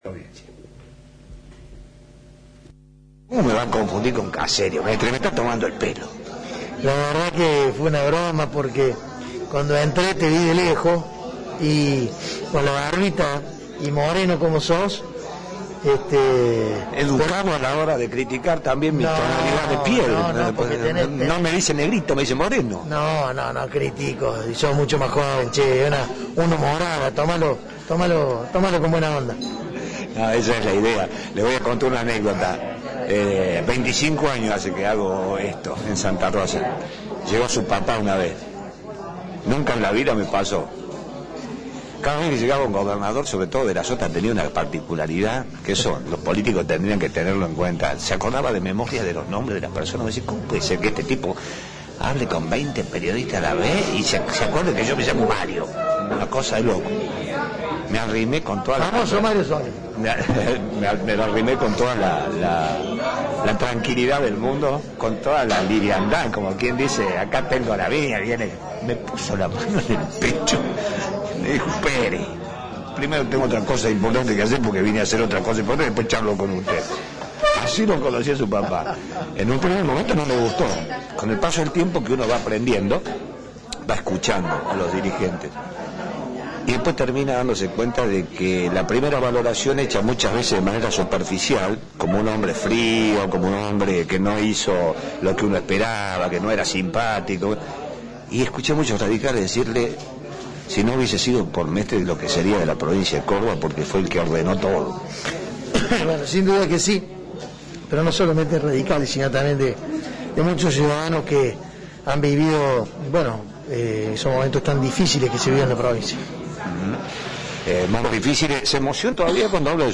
El ex intendente de Córdoba y actual candidato a Diputado se reunió con radicales de la localidad para plantear los objetivos de campaña. Antes, dialogó con la radio que es parte de tu vida y se refirió a la injerencia de Macri en la campaña y analizó aspectos de la situación política actual.